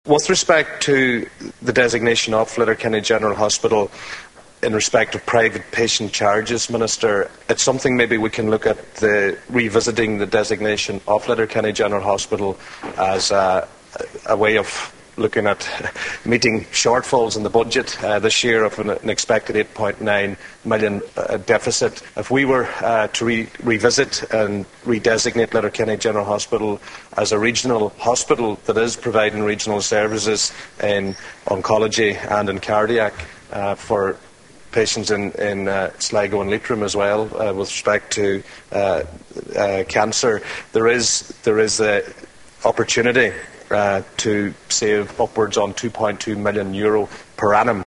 Speaking during a debate on an amendment to the Medical Practioners Bill designed to ease the expected shortage of Junior Doctors, Deputy Joe Mc Hugh said a change in designation would bring in more money from the private side of the hospital’s workload.